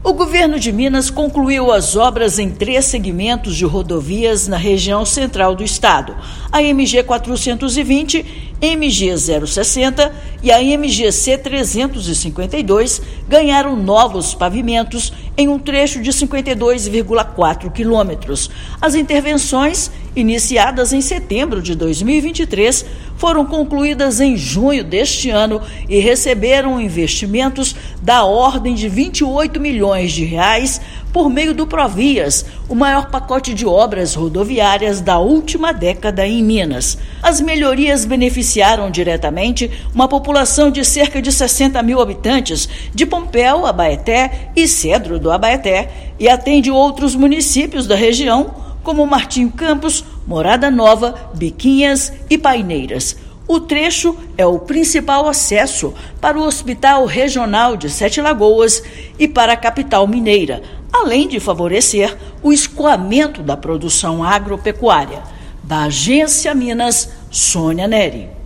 Melhorias foram realizadas em 52,4 quilômetros das MGs 420, 060 e MGC-352, beneficiando diretamente mais de 60 mil habitantes. Ouça matéria de rádio.